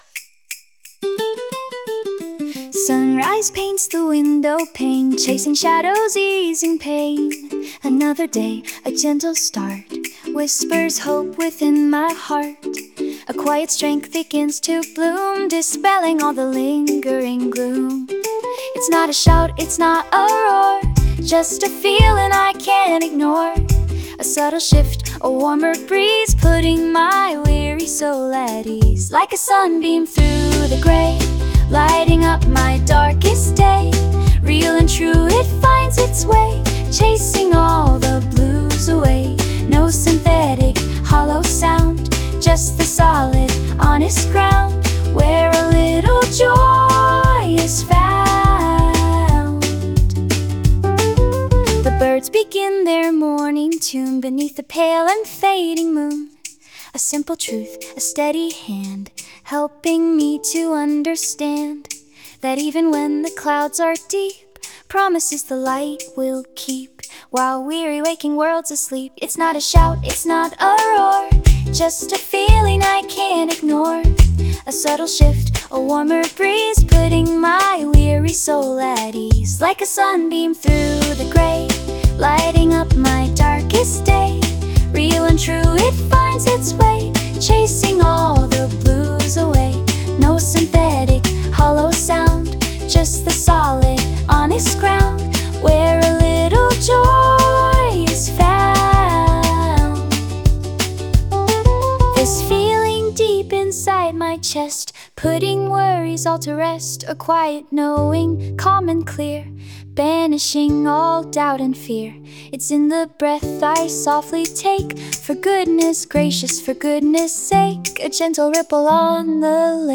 [S. 01 ] § The prompt Something that lifts me up in this dark day, something cheerful but also deep.